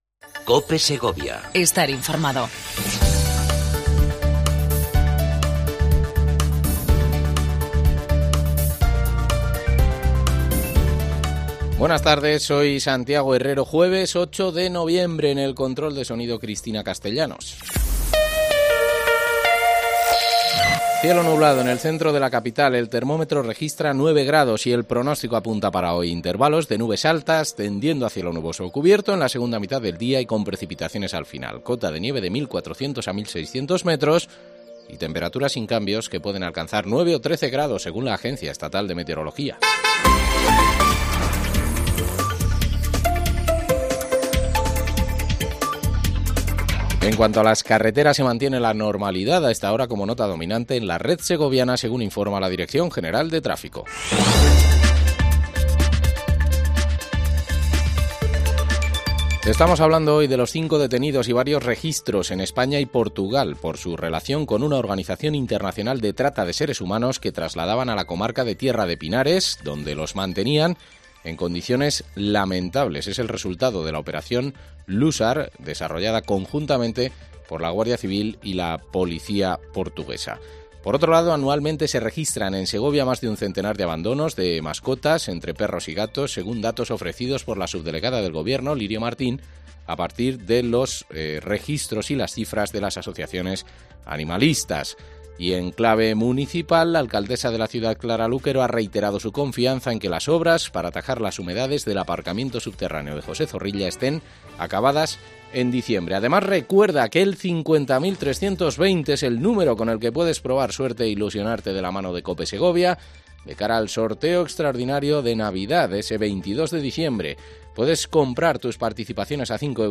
Entrevista Azucena Suárez, Viceportavoz del grupo Municipal del Partido Popular en el Ayuntamiento de la capital